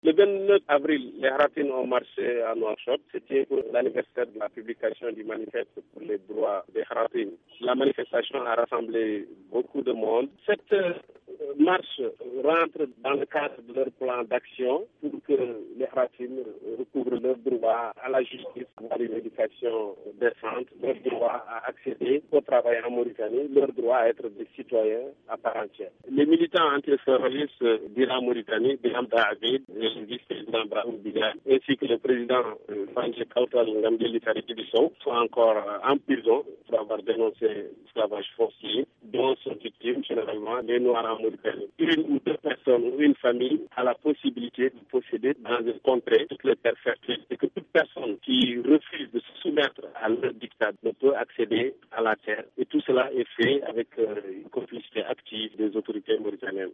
militant anti-escalvagiste à Nouakchott.